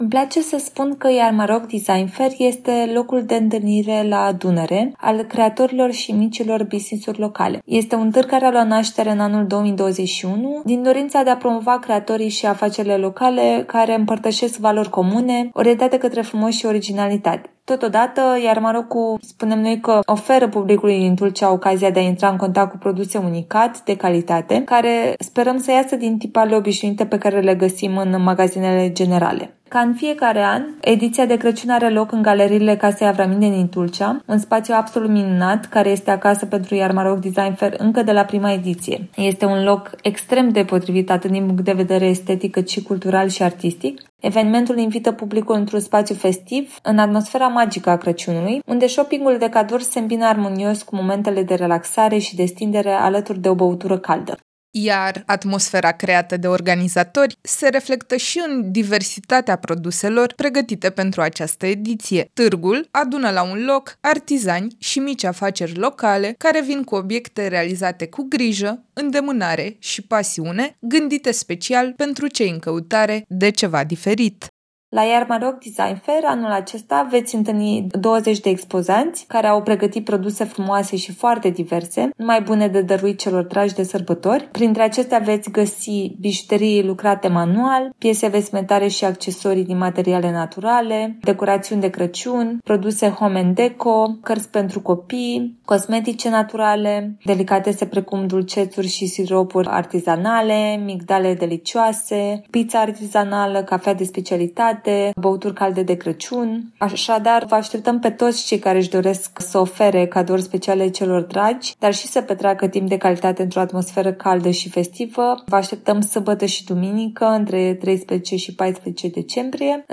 Mai multe detalii aflăm de la